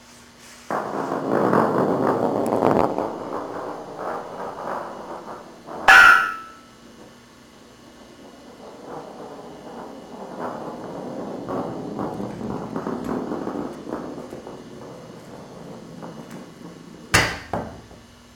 ball bonk clang floor metal rolling thud sound effect free sound royalty free Memes